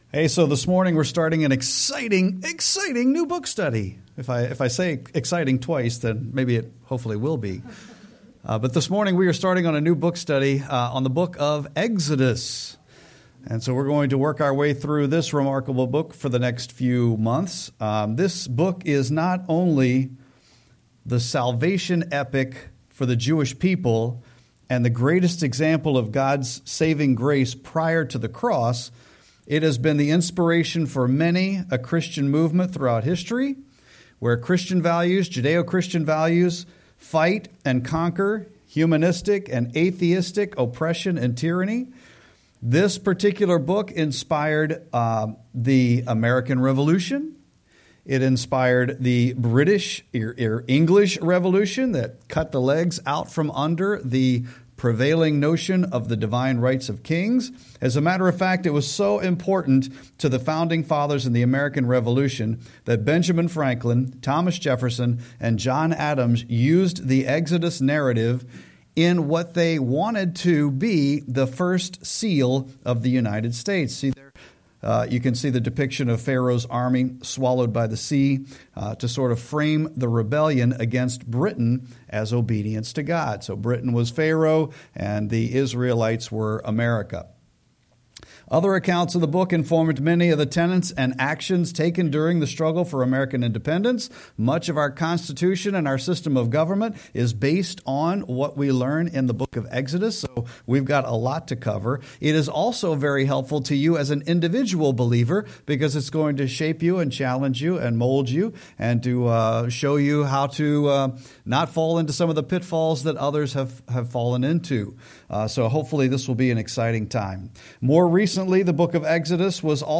Sermon-1-4-26.mp3